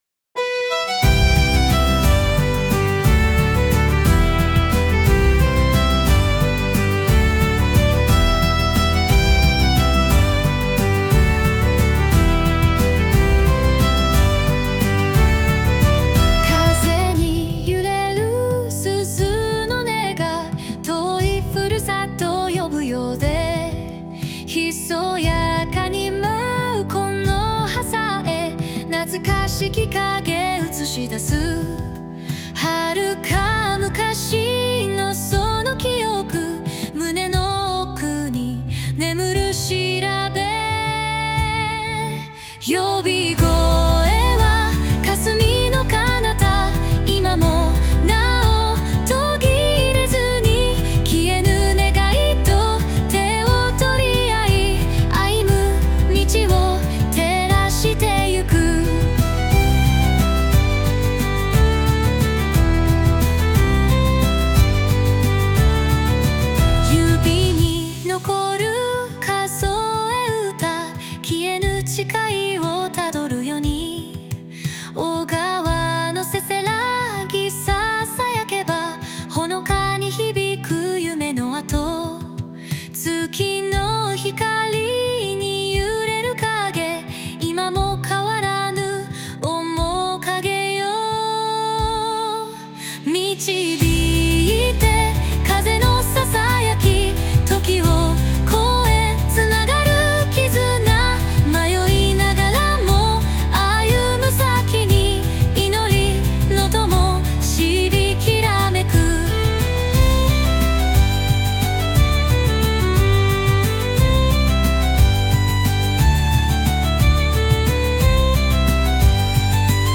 女性ボーカル（邦楽・日本語）曲です。
ファンタジー音楽っぽいのを目指しましたが、なかなか難しいですね。